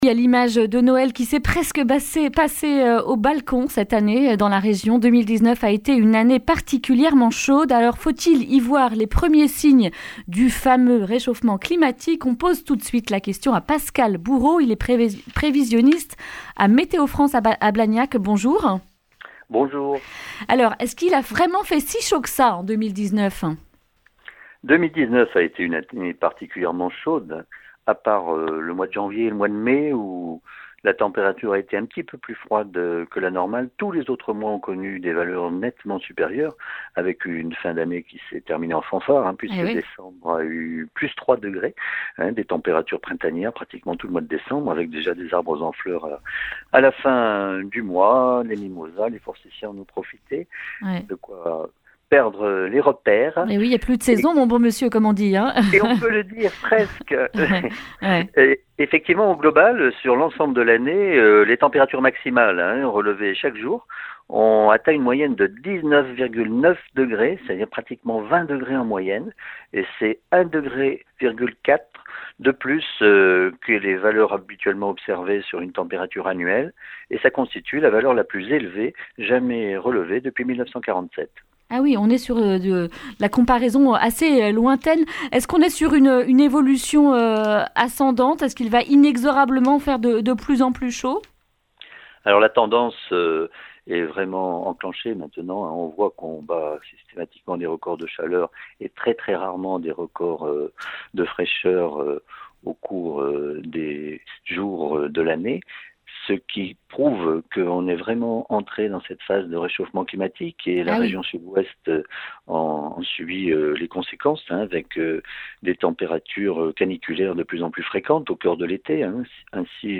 jeudi 9 janvier 2020 Le grand entretien Durée 11 min